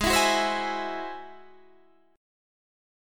G#+7 chord